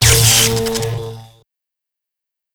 combined RA2 tesla tank shot with RA1 tesla sounds for presence and thematic cohesion